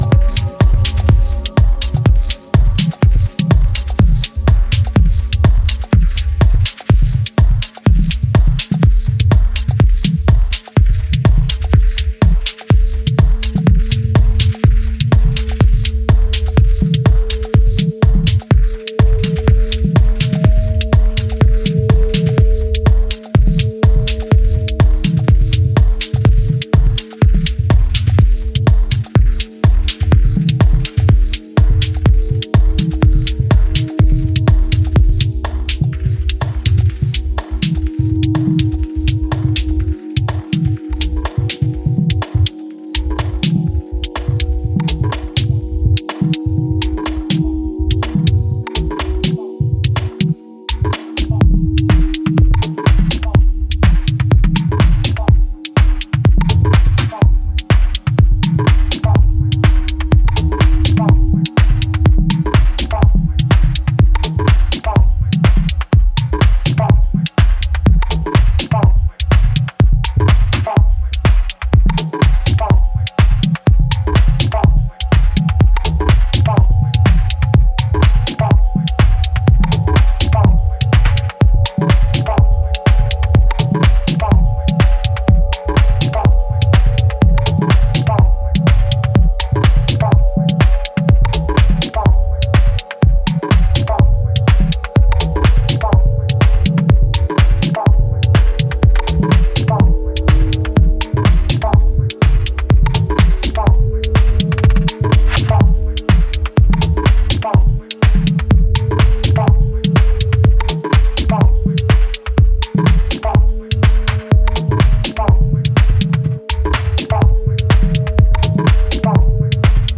minimal-tunnel piece